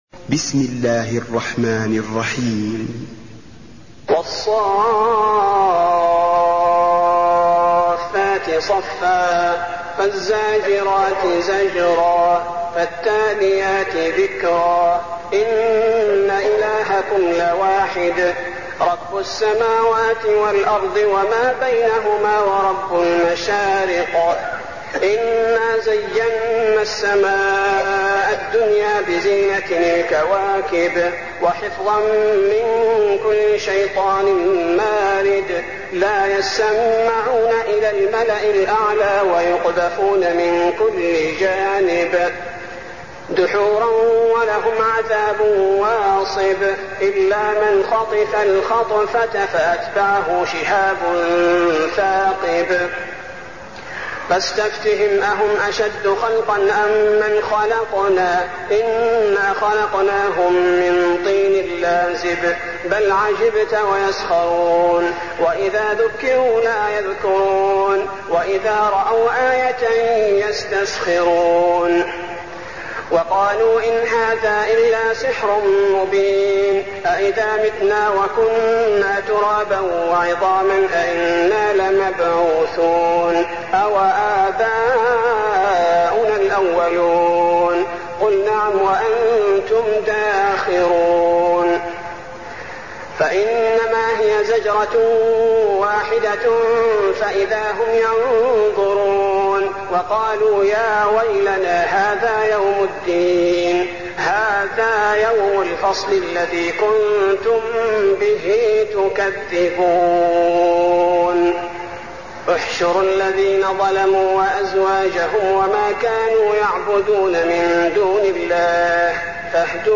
المكان: المسجد النبوي الصافات